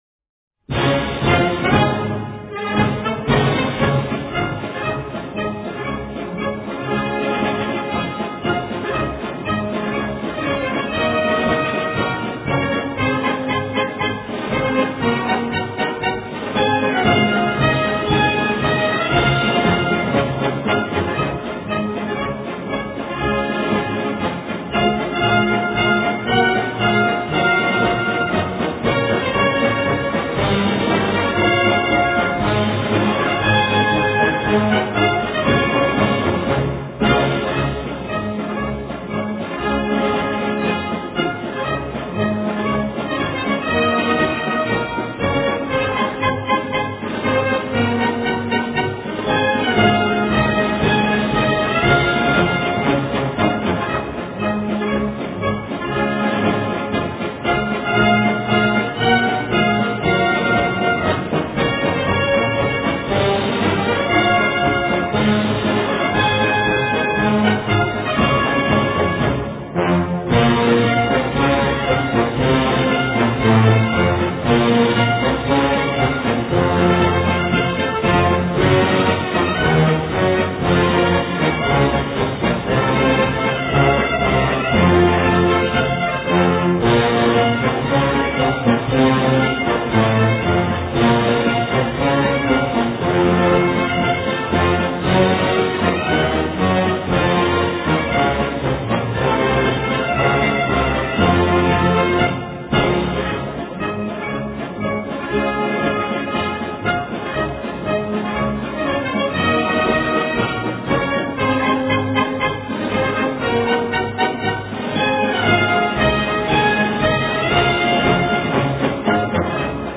Это марш "Герой"